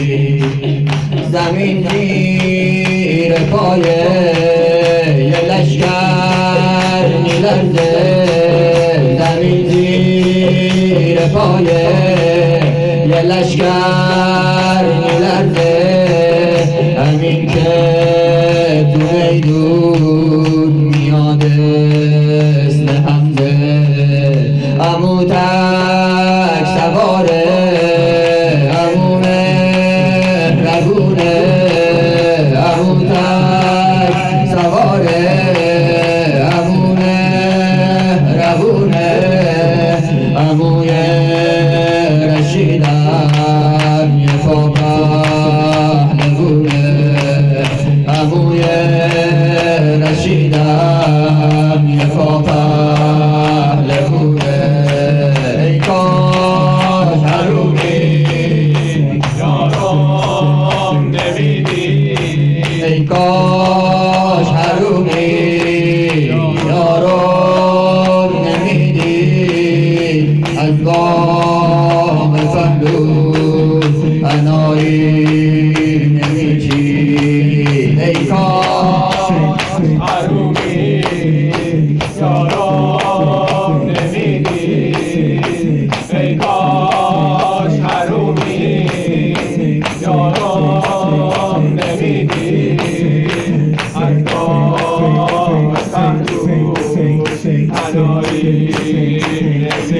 شهادت حضرت حمزه سیدالشهداء(ع)
شور